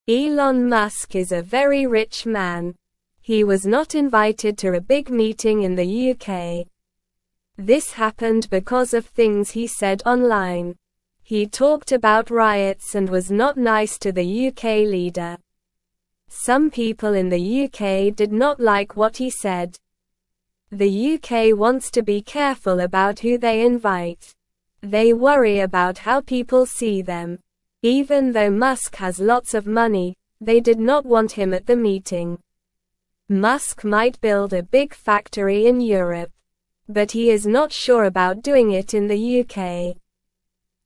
Slow
English-Newsroom-Beginner-SLOW-Reading-Elon-Musk-not-invited-to-UK-meeting-upset.mp3